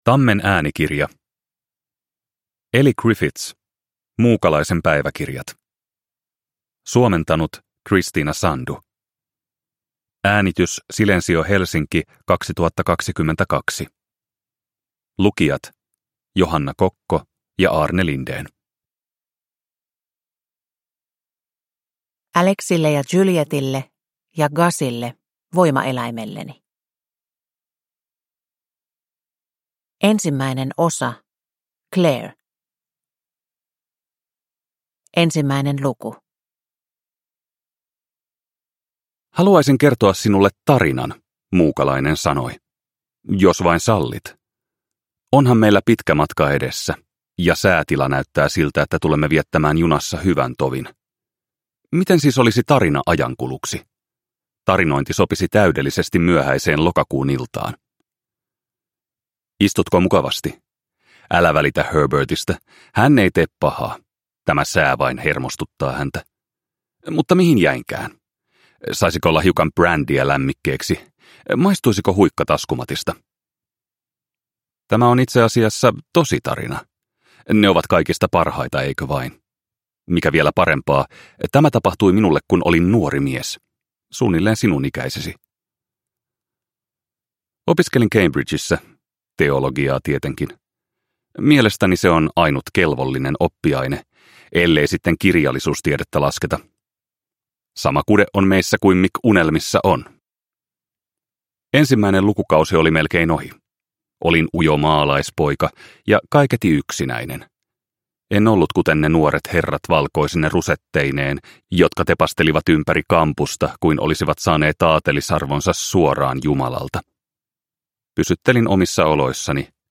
Muukalaisen päiväkirjat – Ljudbok – Laddas ner